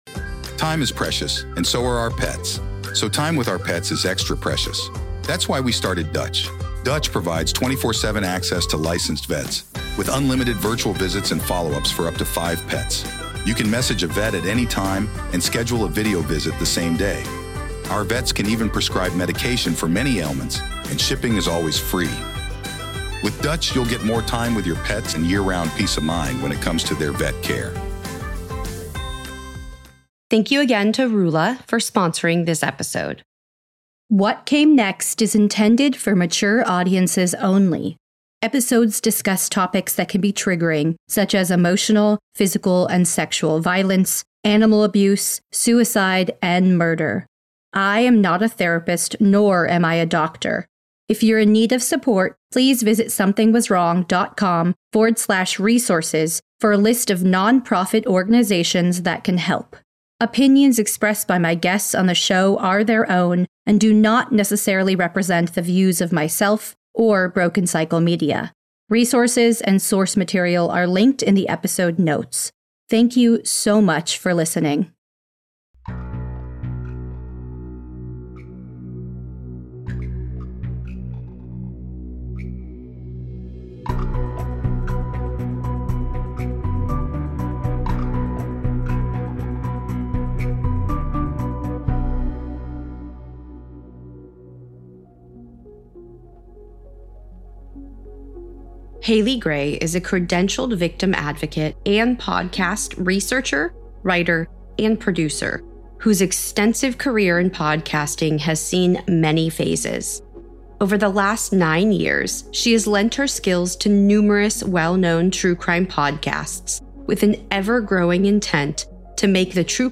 In this very candid conversation